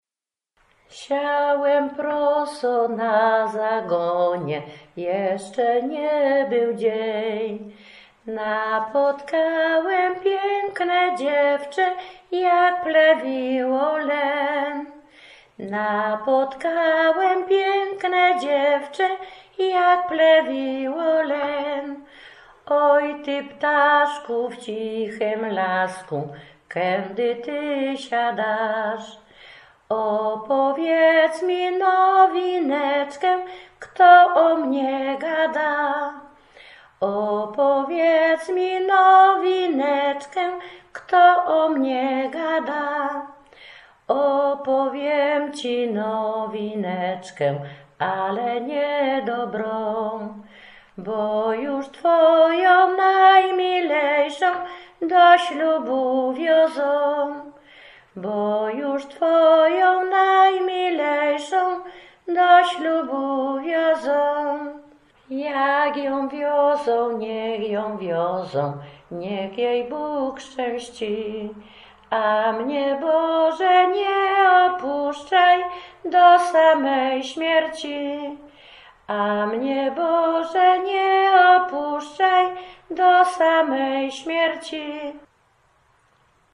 cenioną śpiewaczką ludową